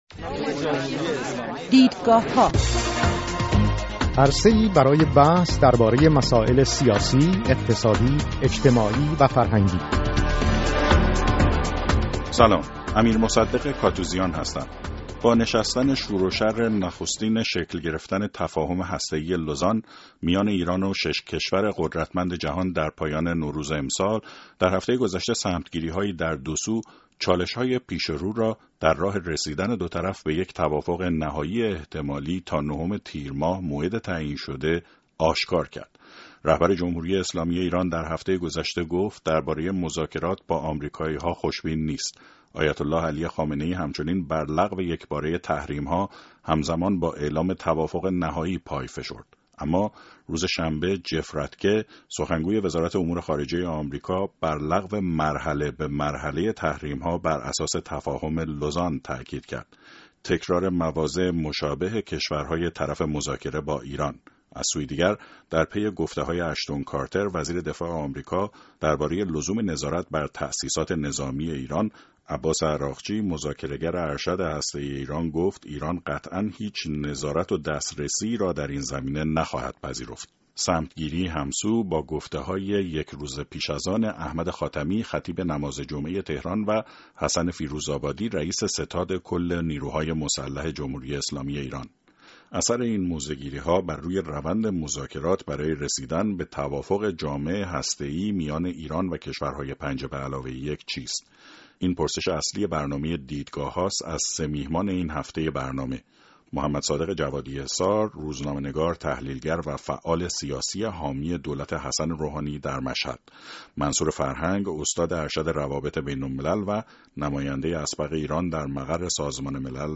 در گفت‌وگو